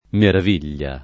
merav&l’l’a] s. f. — tosc. o lett. maraviglia [